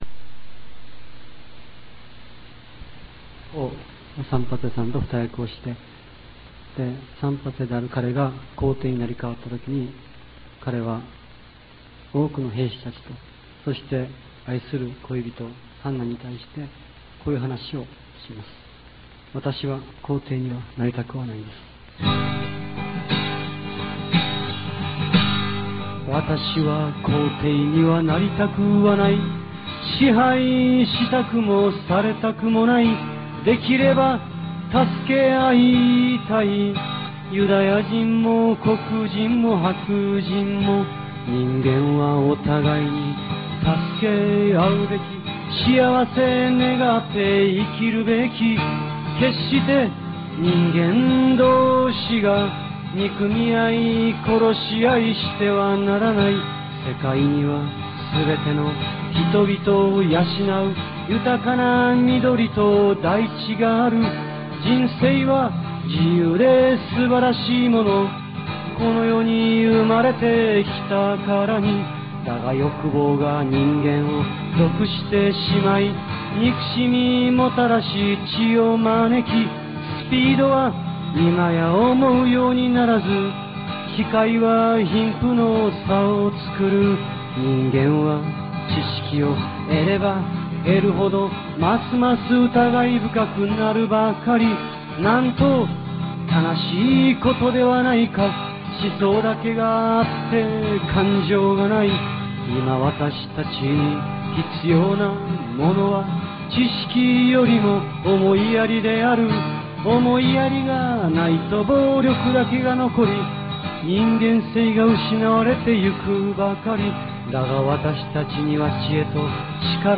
このサイトはフォークソングの音を聞いてもらうのが目的です。
でも、もとがテープでそれをリアルオーディオ形式に変換したものをmp3に再変換したものなので、音質は限りなく悪い。